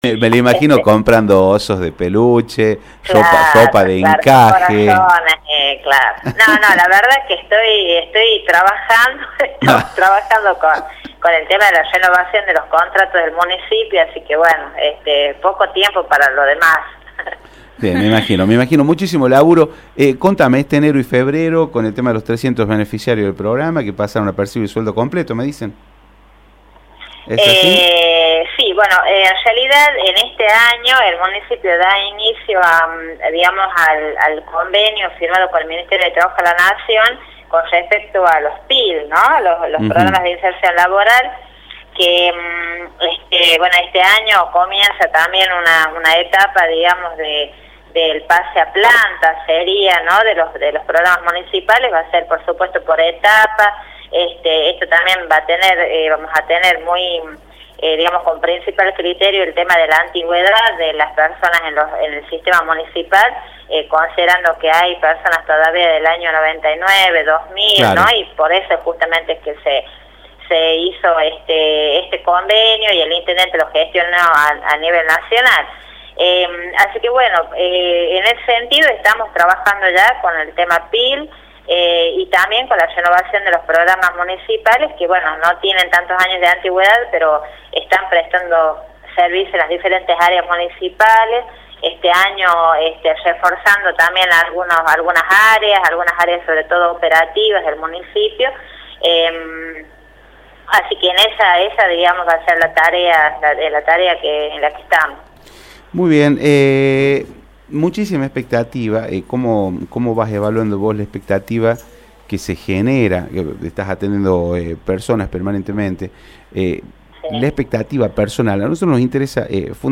Nancy Nardillo, titular de la Subsecretaría de Empleo Municipal, por Radio Rioja
En diálogo con Radio Rioja, Nardillo dio detalles del traspaso previsto para el 2013, luego de los desencuentros con las autoridades provinciales.